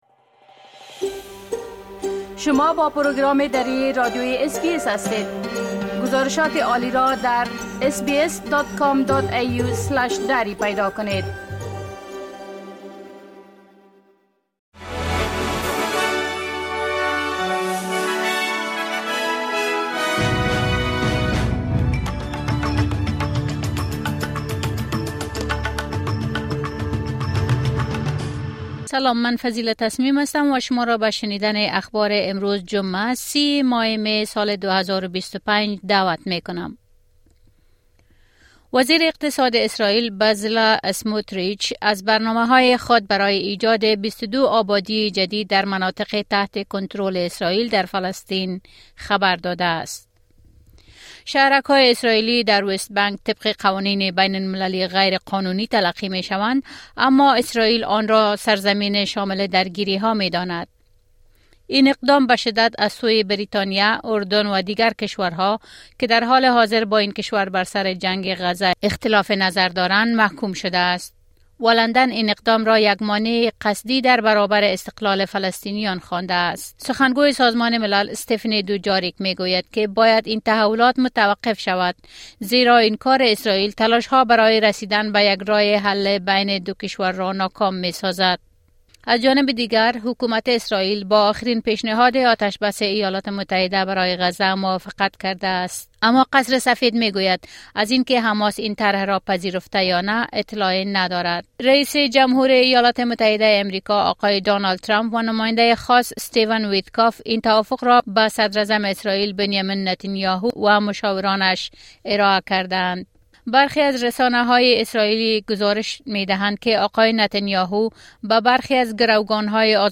خلاصه مهمترين اخبار روز از بخش درى راديوى اس بى اس